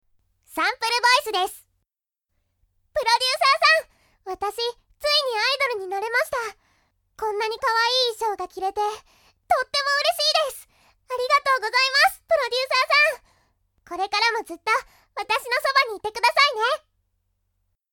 ＊＊得意ジャンル＊＊ ボイス・・・ロリ～少女、ハキハキ、元気系 ボーカル・・・電波系（合いの手・台詞可）、ガールズポップ、早口曲等 サイトからの直接のご依頼も可能です。